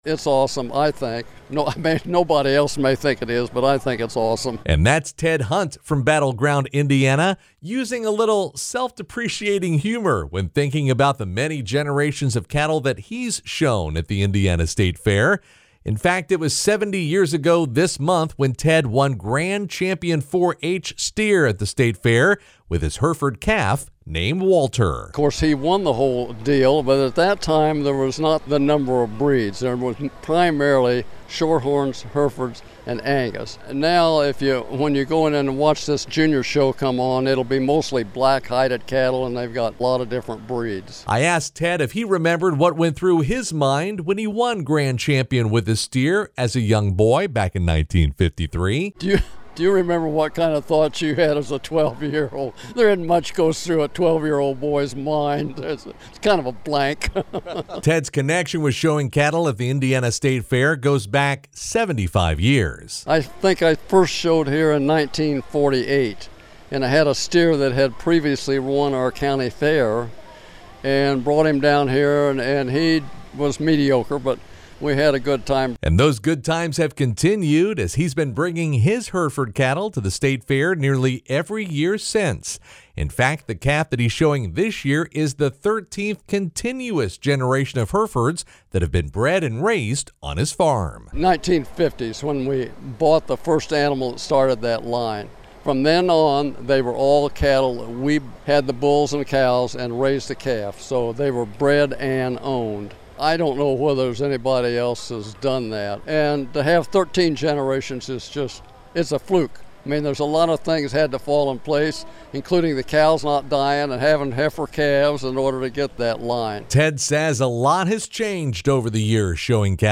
radio news report